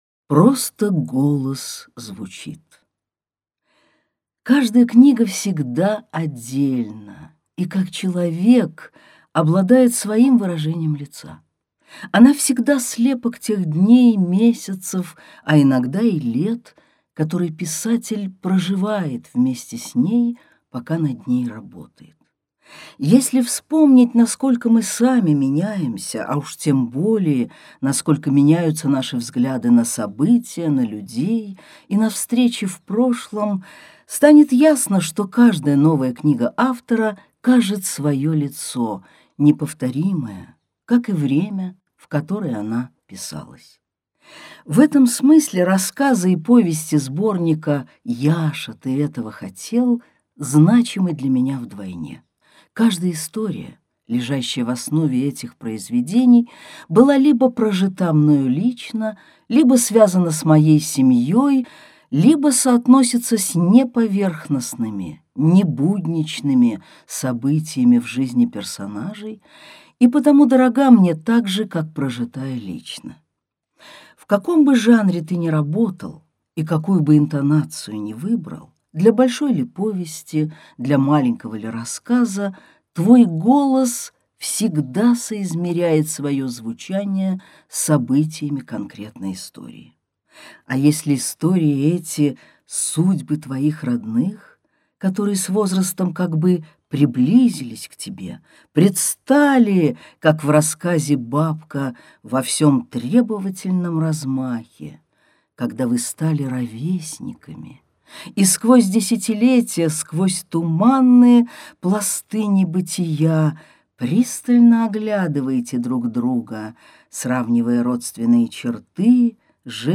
Аудиокнига Яша, ты этого хотел?